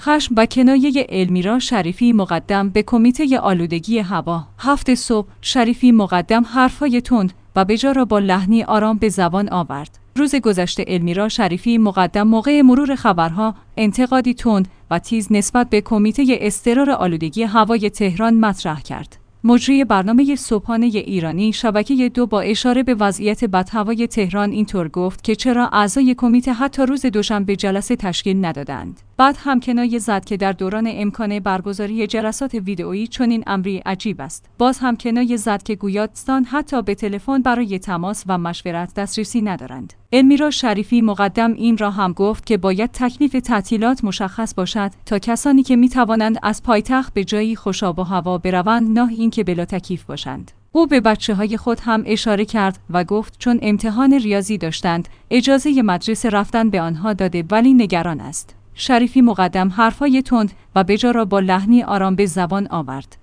هفت صبح/ شریفی‌مقدم حرف‌های تند و بجا را با لحنی آرام به زبان آورد. روز گذشته المیرا شریفی‌مقدم موقع مرور خبرها، انتقادی تند و تیز نسبت به کمیته اضطرار آلودگی هوای تهران مطرح کرد.